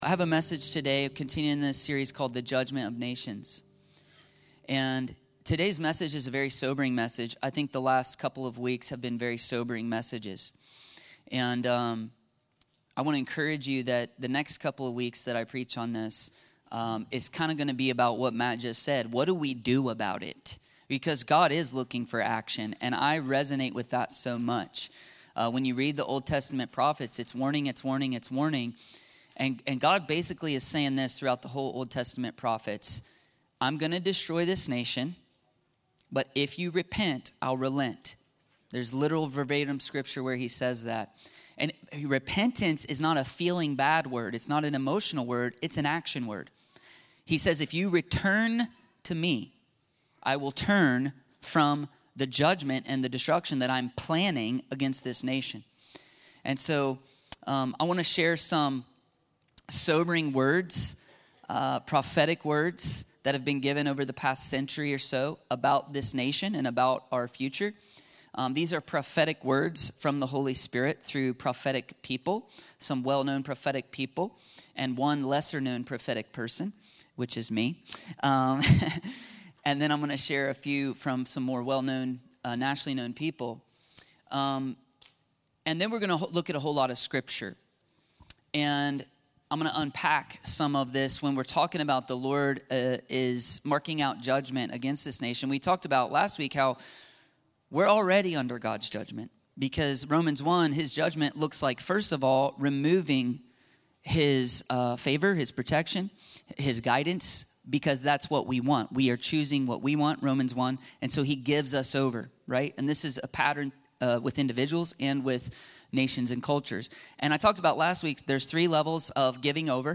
The Responsibility and Judgement of a Superpower - The Judgement of Nations ~ Free People Church: AUDIO Sermons Podcast